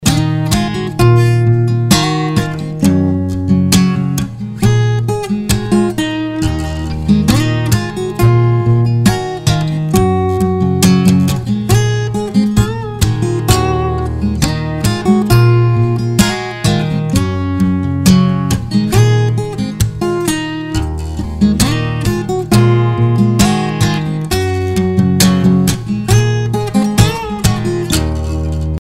• Качество: 256, Stereo
гитара
без слов
Cover
инструментальные
акустическая гитара
Лирическая мелодия
на акустической гитаре